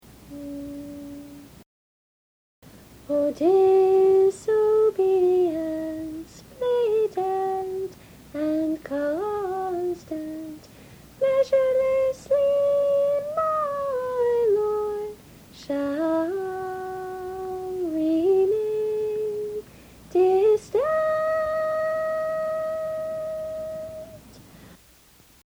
See individual song practice recordings below each score.
Many, especially the ones below the individual scores, were recorded on an old, portable cassette tape and have some distortion.